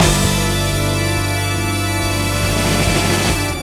HIT BIGBAN05.wav